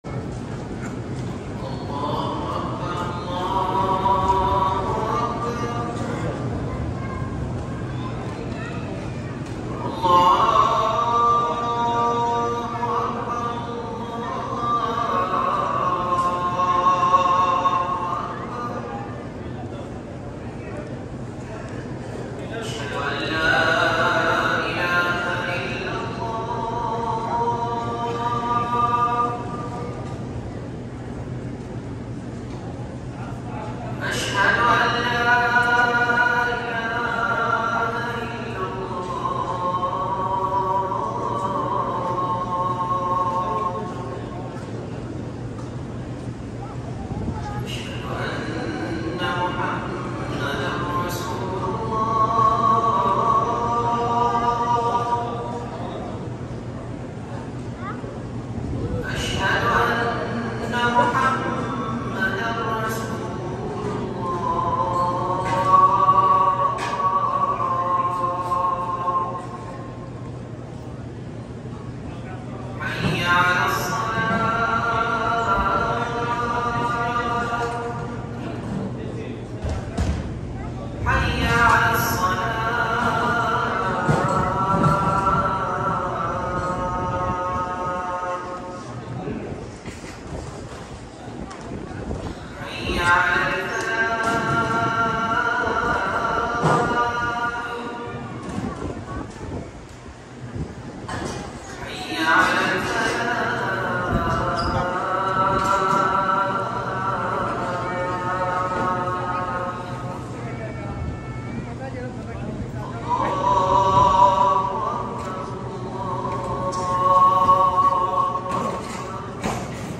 الأذان الأول لصلاة الفجر